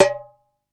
Hand Darbuka 02.wav